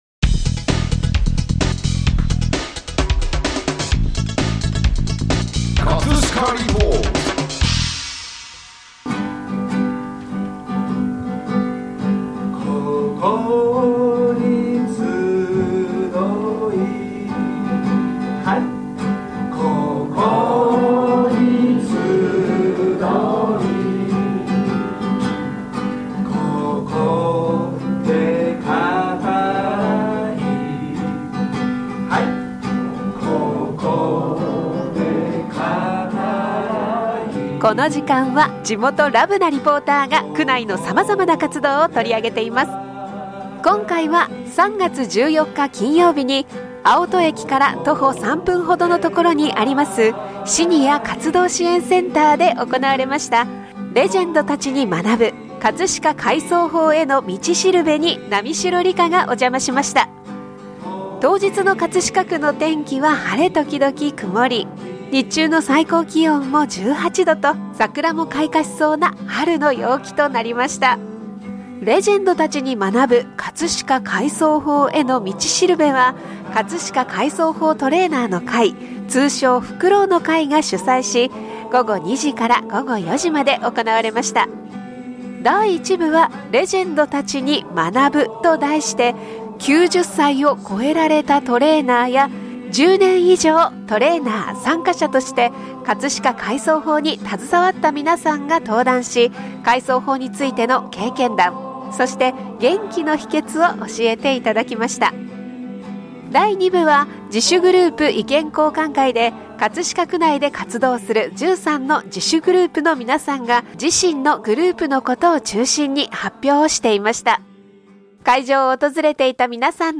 【葛飾リポート】
第2部は、「自主グループ意見交換会」で、葛飾区内で活動する13の自主グループの皆さんが自身のグループのことを中心に発表していました。 会場を訪れていた皆さんの声をどうぞお聞きください！